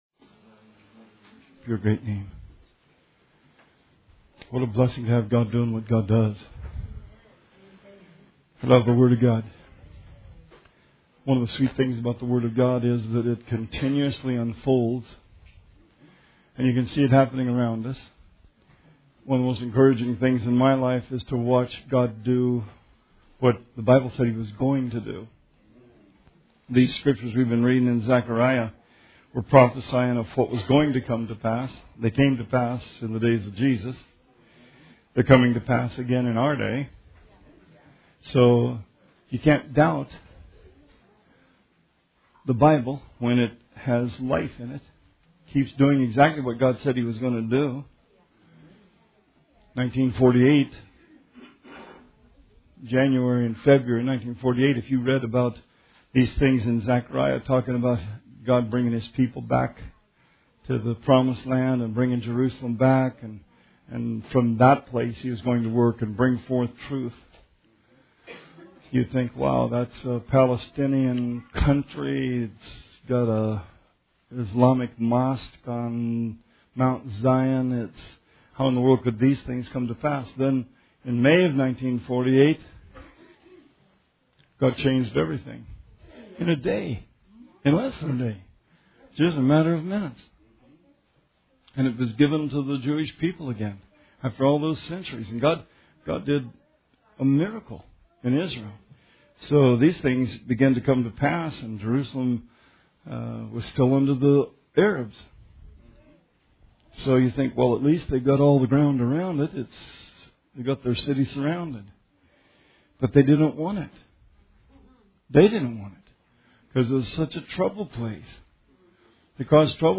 Sermon 4/15/18